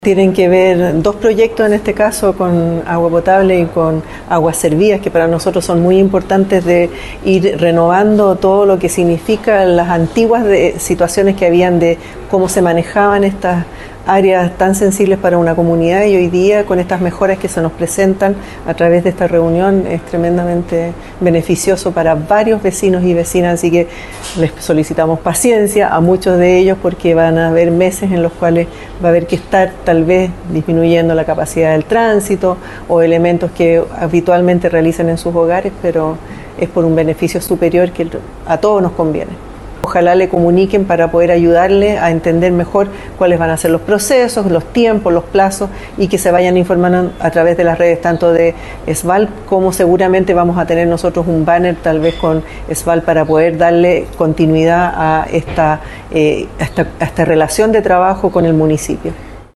La Alcaldesa de San Felipe, Carmen Castillo, destacó la inversión y el trabajo de modernización que está realizando la sanitaria.